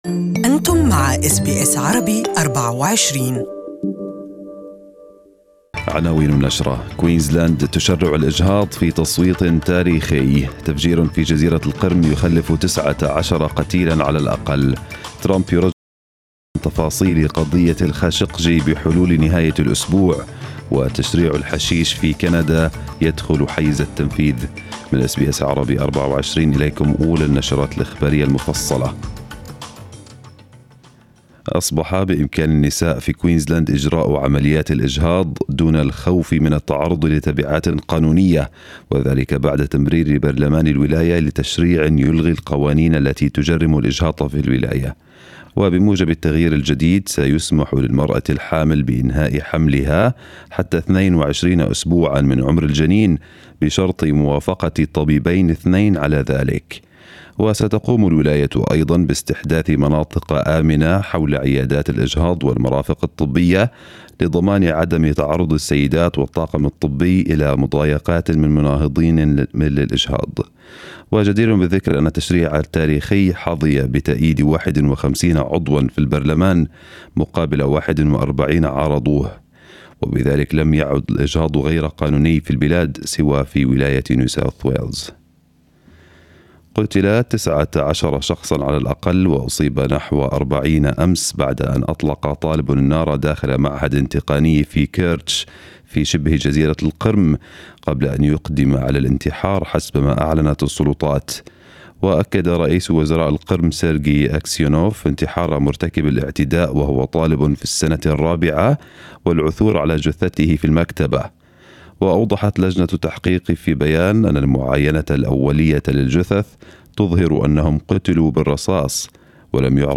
News Bulletin of the morning